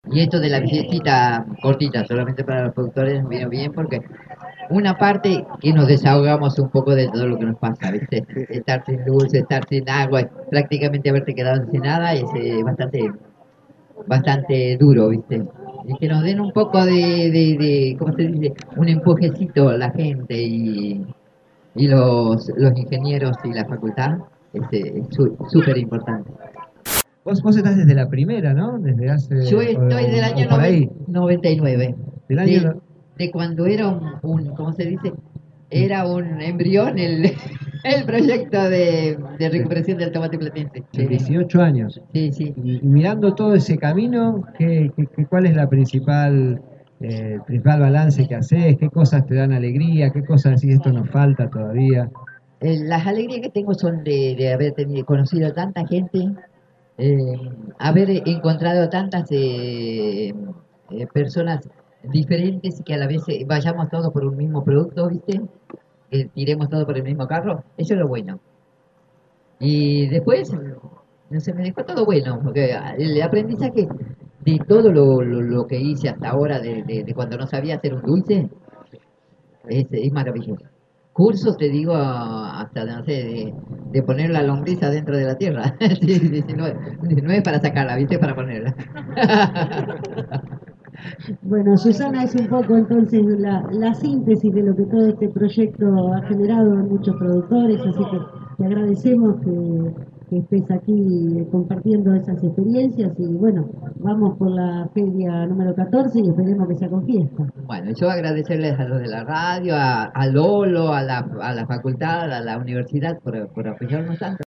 (13/02/17) Radio Estación Sur acompañó la 13ª Fiesta del Tomate Platense, que se desarrolló el sábado 11 de febrero en la Estación Experimental Julio Hirschhorn, en 66 y 167 (Los Hornos).
A continuación compartimos diversos testimonios recogidos durante la jornada: